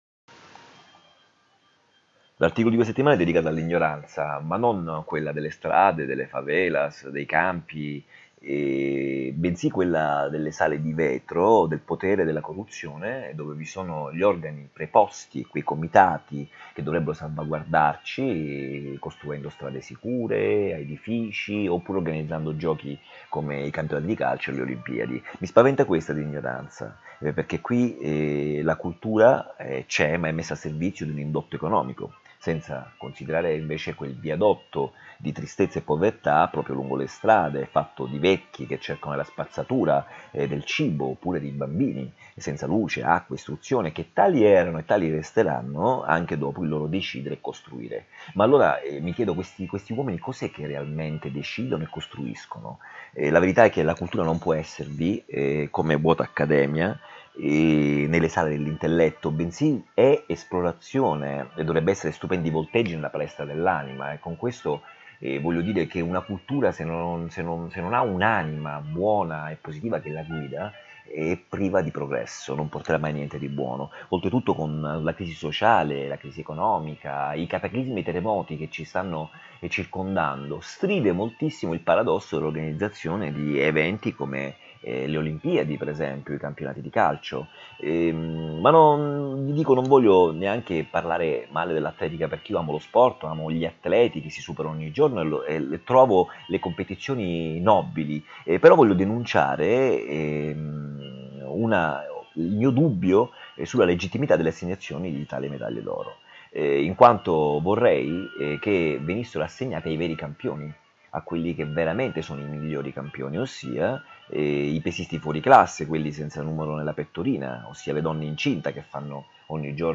5 MINUTI D’AUDIO Riprodurranno la riflessione dell’articolo a voce alta, perché abbiano accesso all’ascolto i ciechi e quelli tra noi che pur avendo la vista sono diventati i veri Non Vedenti.